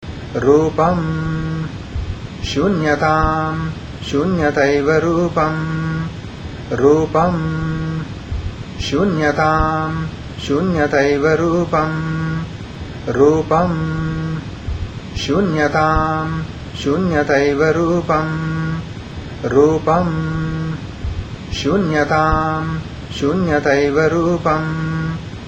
1_5_monk.mp3